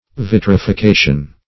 Vitrification \Vit`ri*fi*ca"tion\, n.